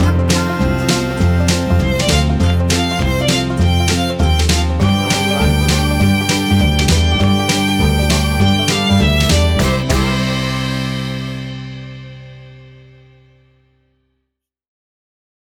WAV Sample Rate: 16-Bit stereo, 44.1 kHz
Tempo (BPM): 100